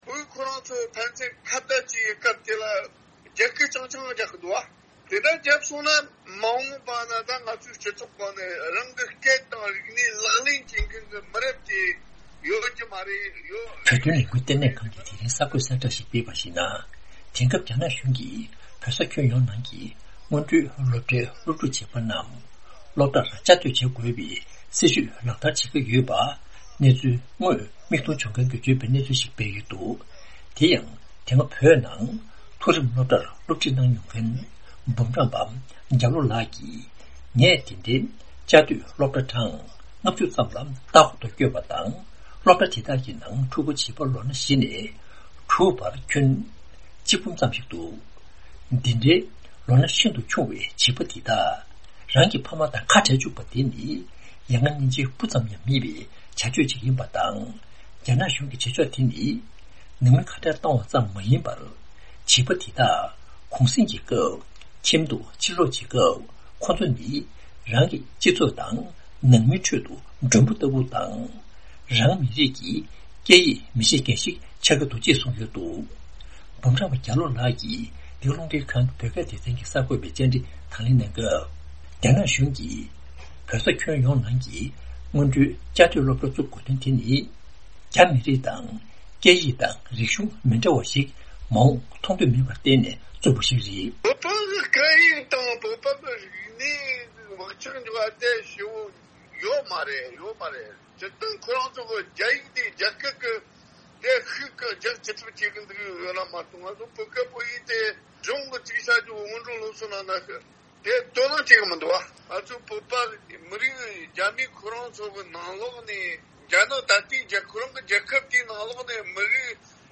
བོད་ཀྱི་སློབ་གསོའི་ལམ་ལུགས་ཐད་ཀྱི་དཔྱད་ཞིབ་པ་ཞིག་ལ་བཅར་འདྲི་ཞུས་ནས་གནས་ཚུལ་ཕྱོགས་བསྒྲིགས་གནང་བ་ཞིག་གསན་རོགས་གནང་།།